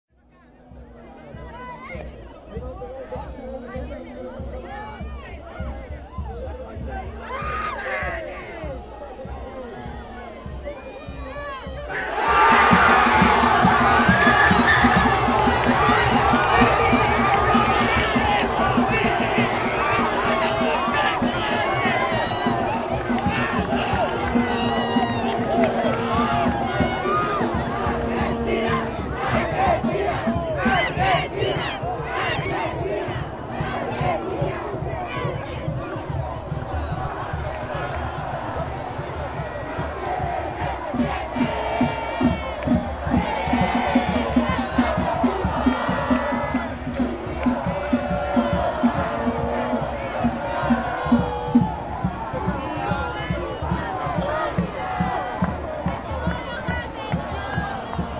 A través de un novedoso experimento, durante el partido contra Suiza se instaló en Plaza San Martín un decibelímetro, que midió la presión sonora, en medio de la hinchada argentina frente a la pantalla gigante.
El grito de los porteños en Plaza San Martín
El nivel más alto captado por el decibelímetro fue durante el gol de Di María, que llegó a los 111 dB.